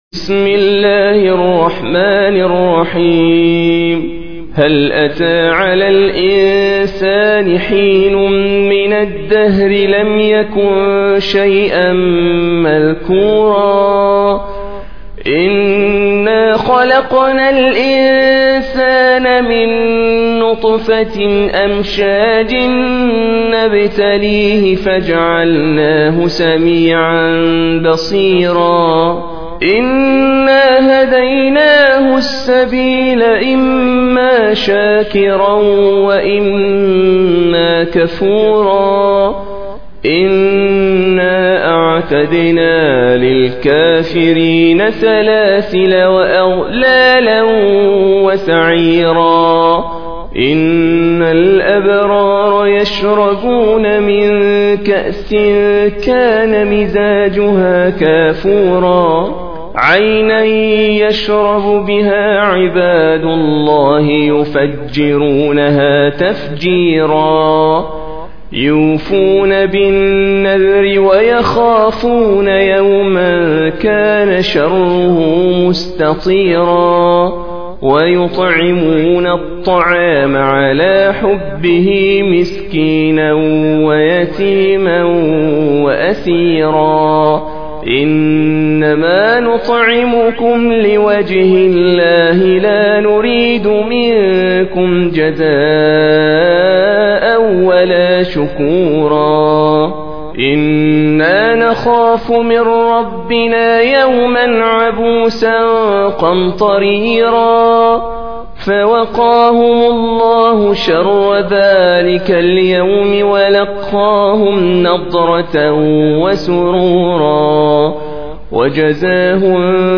Surah Sequence تتابع السورة Download Surah حمّل السورة Reciting Murattalah Audio for 76. Surah Al-Ins�n or Ad-Dahr سورة الإنسان N.B *Surah Includes Al-Basmalah Reciters Sequents تتابع التلاوات Reciters Repeats تكرار التلاوات